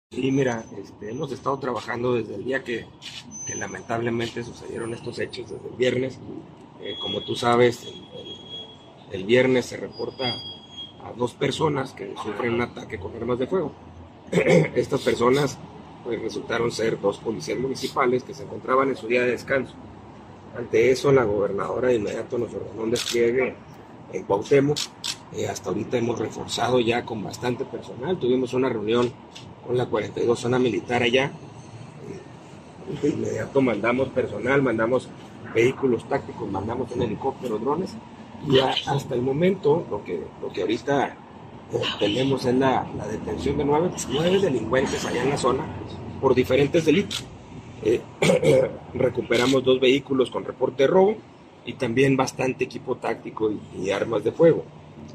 AUDIO: GILBERTO LOYA, TITULAR DE LA SECRETARÍA DE SEGURIDAD PÚBLICA DEL ESTADO (SSPE)